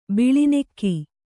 ♪ biḷi nekki